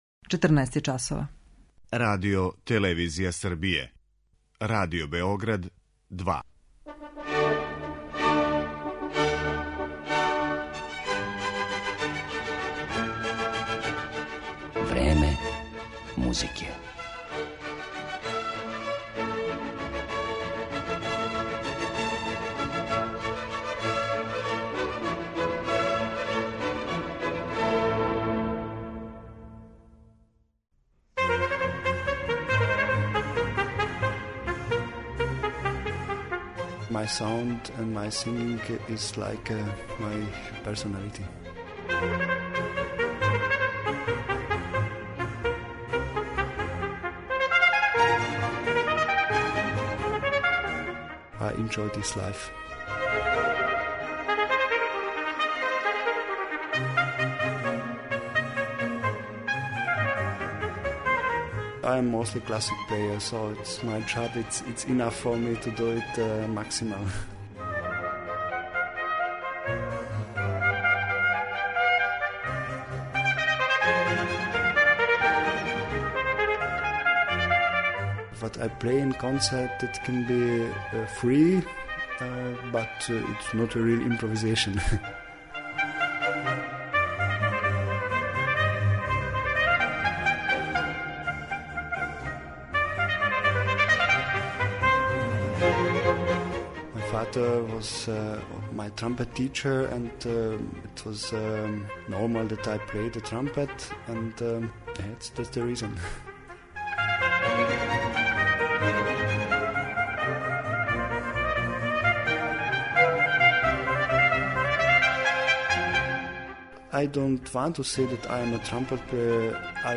Габор Болдоцки - Паганини на труби
Једном од најбољих трубача данашњице, мађарском солисти Габору Болдоцком, кога критичари описују као "Паганинија на труби" и наследника славног Мориса Андреа, посвећена је данашња емисија Време музике.
Слушаћете га како изводи композиције Хенрија Персла, Карла Филипа Емануела Баха, Герга Фридриха Хендла, Волфганга Амадеуса Моцрта и Јохана Себастијана Баха.